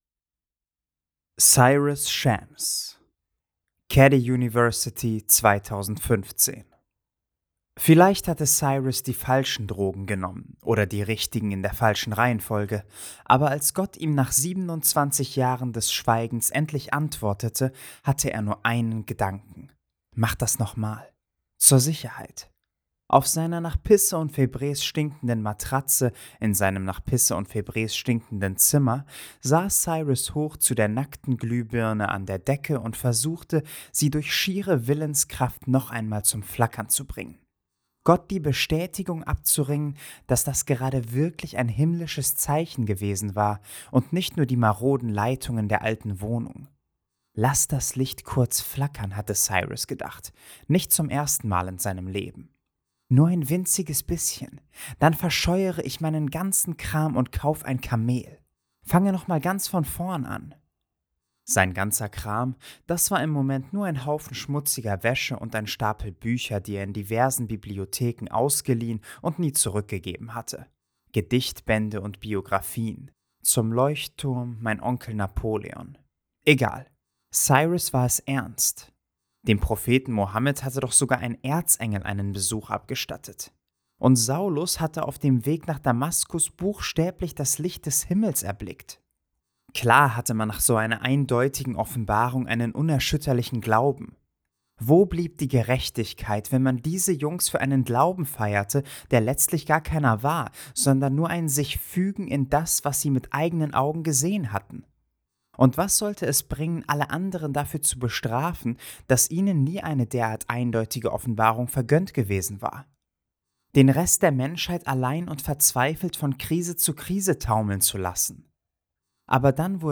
Genre: Literatur & Belletristik, Romane